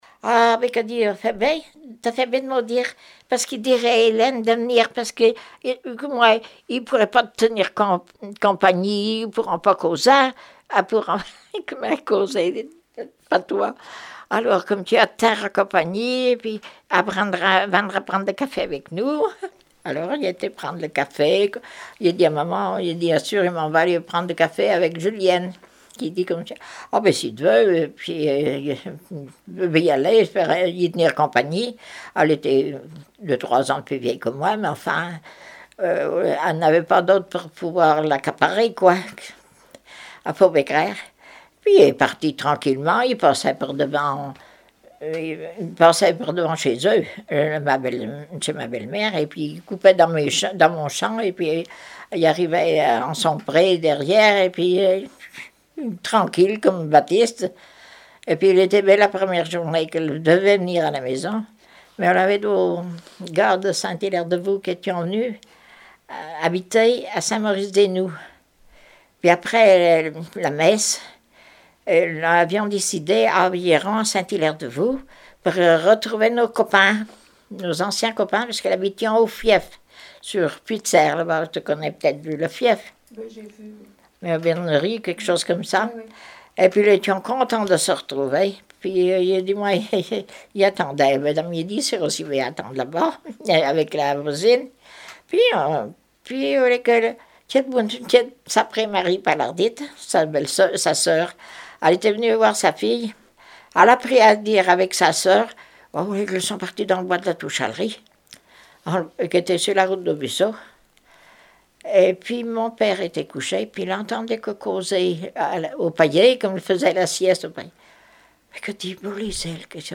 Témoignage sur la vie de l'interviewé(e)